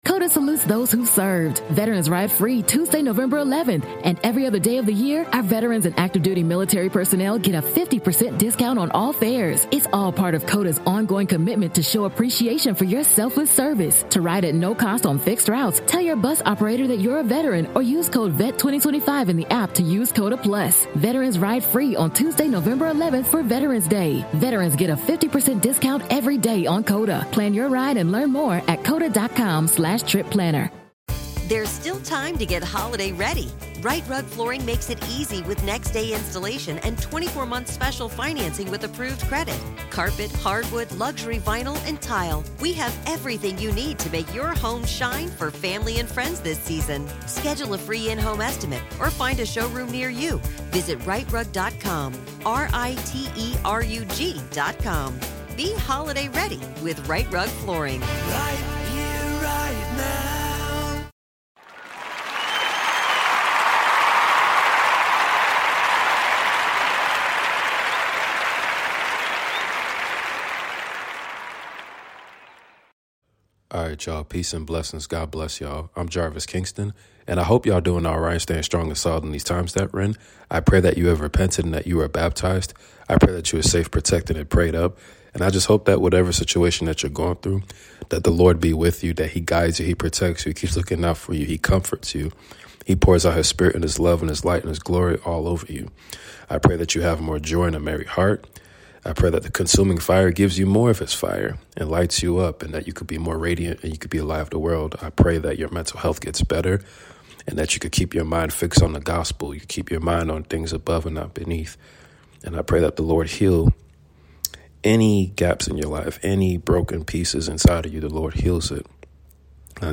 Book of Genesis reading about Joseph, Judah, Jacob.